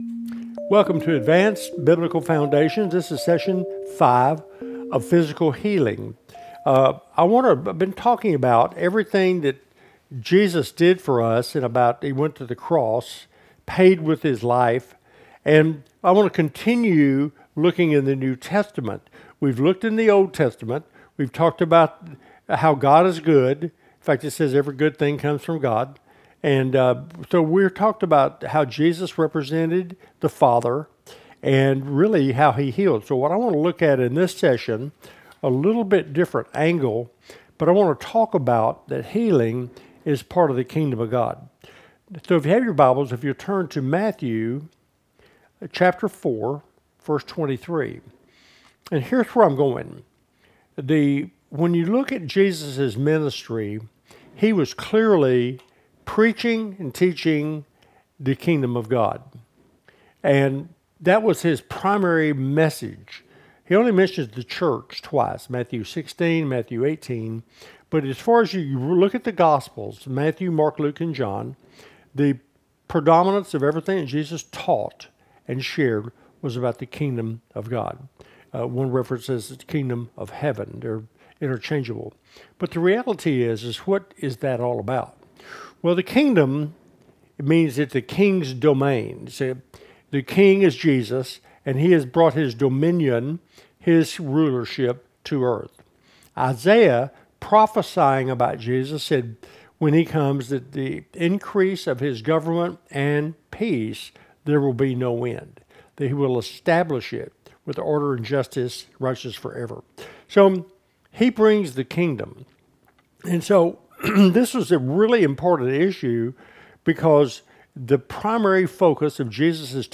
With 20-minute teachings, perfect for personal study or small group discussions, every episode concludes with a powerful prayer